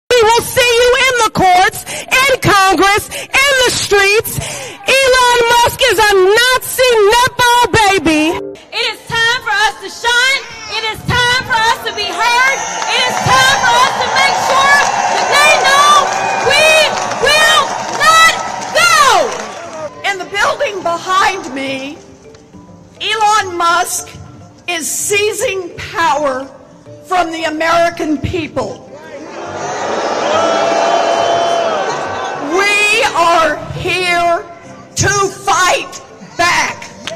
Here’s a short montage of them melting down Wednesday over Elon Musk asking on our behalf.